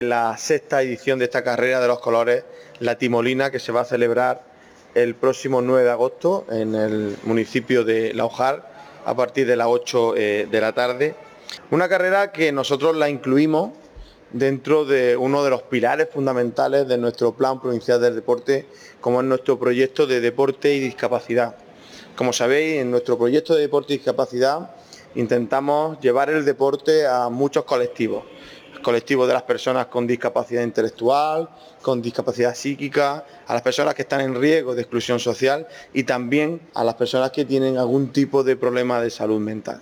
La Diputación de Almería ha presentado en el Pabellón Moisés Ruiz una nueva edición de la Carrera Urbana de Colores Solidarios de Laujar. En concreto, se trata de la sexta edición de una cita deportiva en favor de la salud mental, organizada por la Asociación El Timón.
04-08_timolina_diputado.mp3